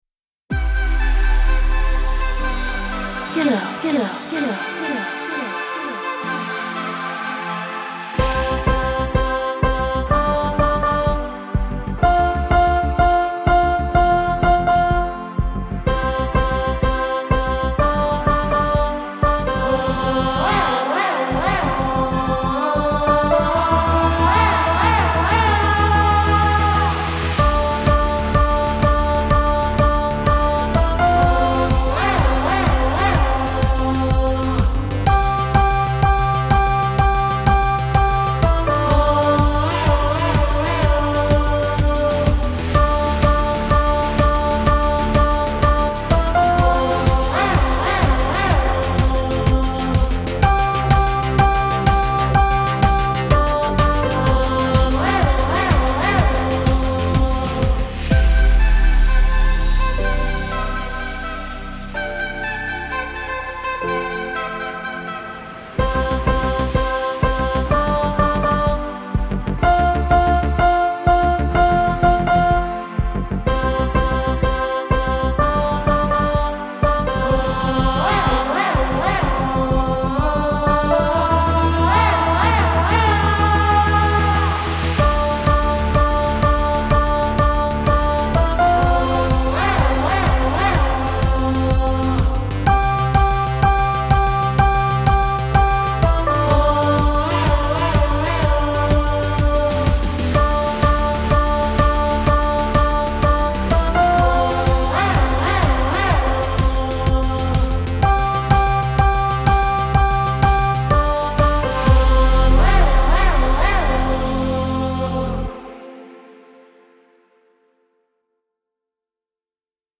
Lyssna och sjung er egen text med melodin.